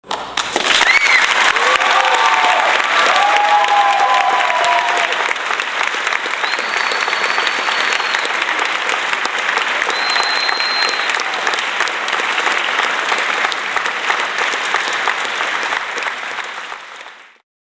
Aplausos Longos
Som de aplausos longos e empolgados.
aplausos-longos.mp3